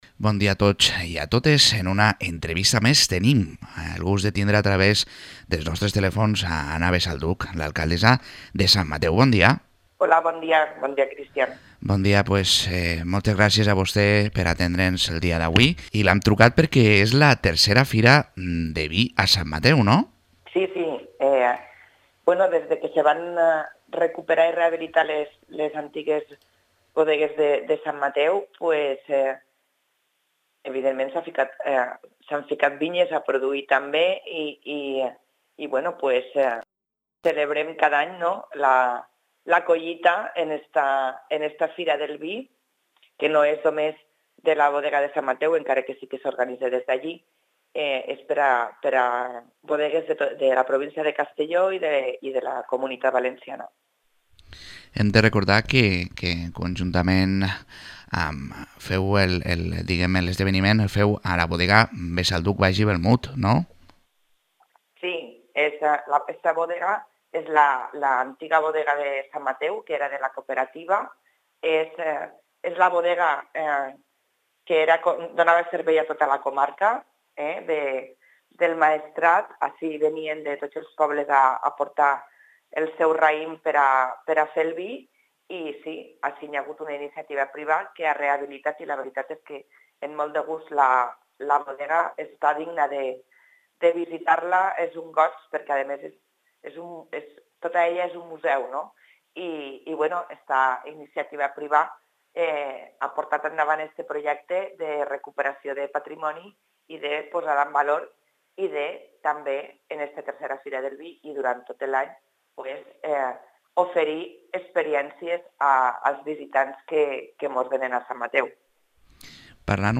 Entrevista a Ana Besalduch, alcaldessa de Sant Mateu